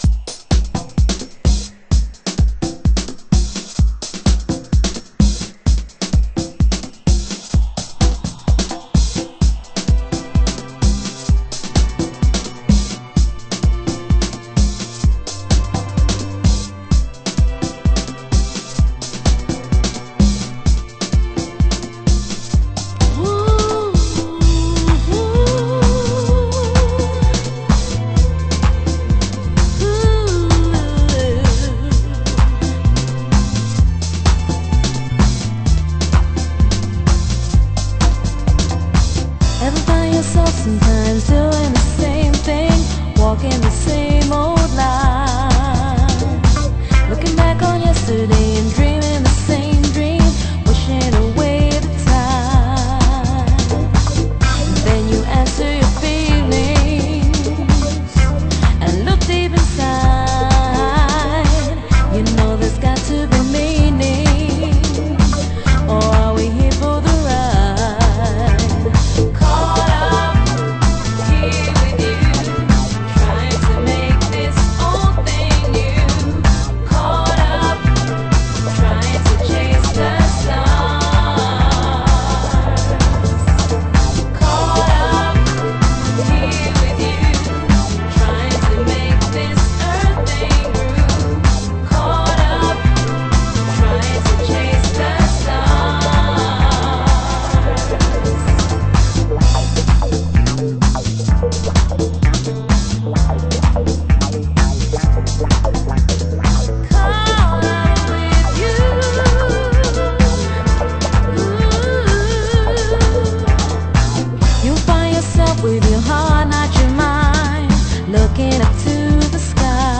HOUSE MUSIC
中盤 　 　盤質：盤面良好ですが少しチリパチノイズ有　　ジャケ：右上部に軽い折れ有